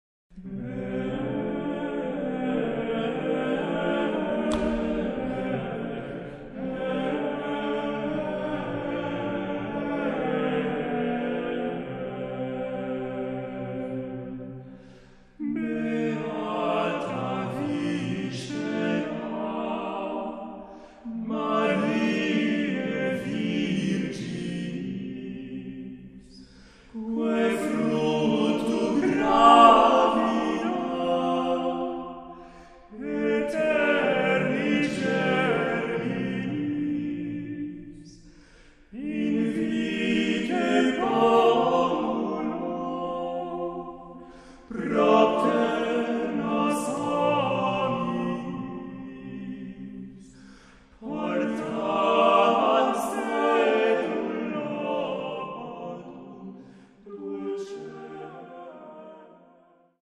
* Gravado na Igreja do Carmo, Beja, Portugal em 2004